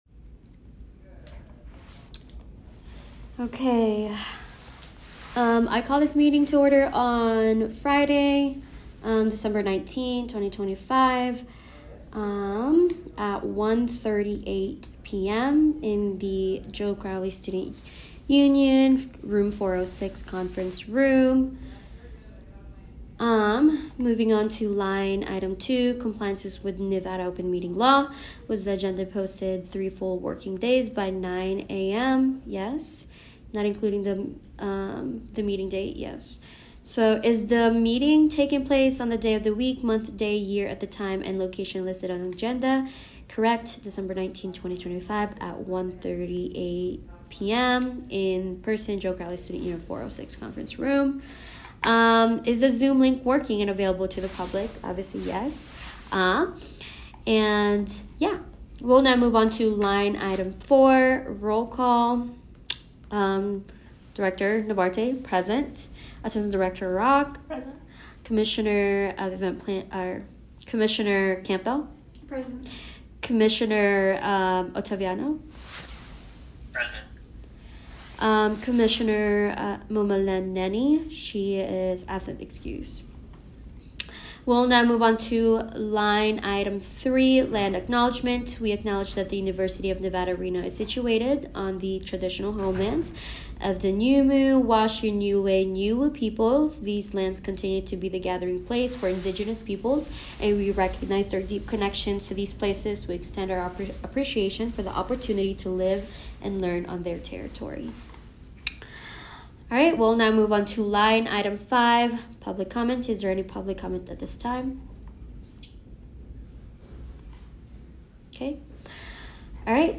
Location : Joe Crowley Student Union 406 Conference Room